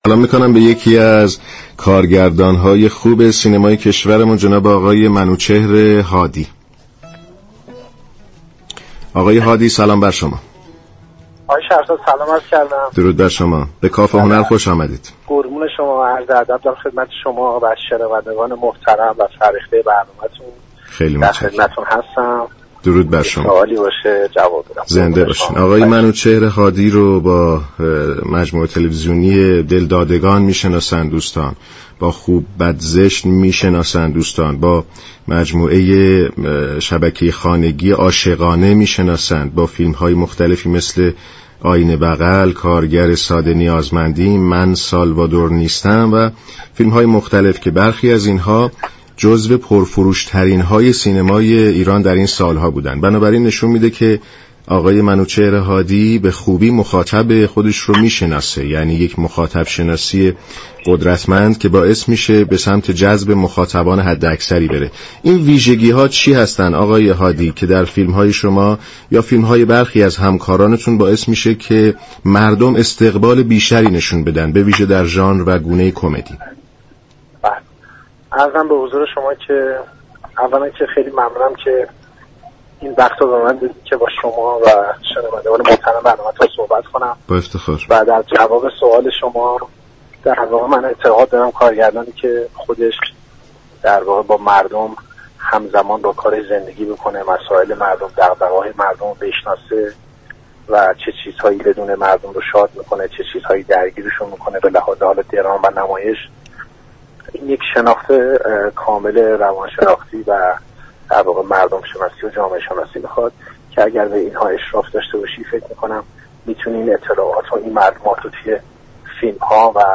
منوچهر هادی كارگردان سینما و تلویزیون در گفت و گو با برنامه كافه هنر رادیو ایران گفت: كارگردانی كه دغدغه مردم دارد و می داند چه چیزی آنان را شاد و چه چیزی غمگین می كند، بر مسائل روانشناسی، جامعه شناسی و مردم شناسی اشراف كامل دارد ، می تواند حرف دل مخاطب را در قالب یك اثر ارائه دهد.